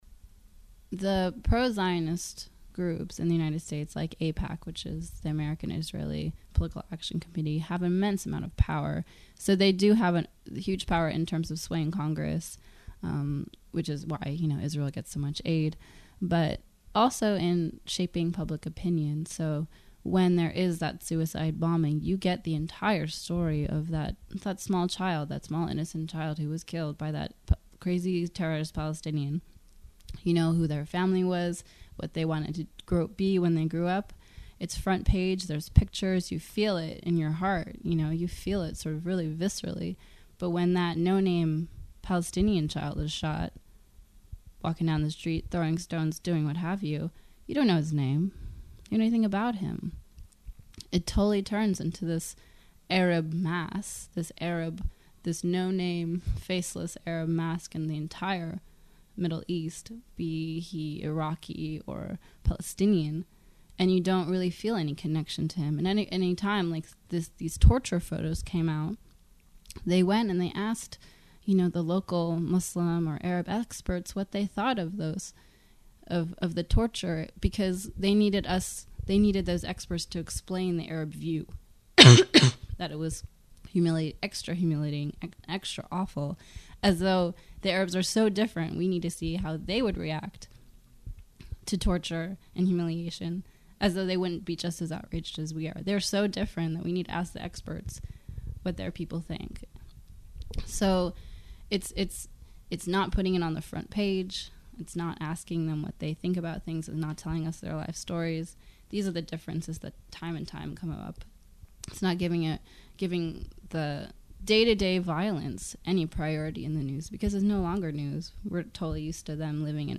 Palestine Interview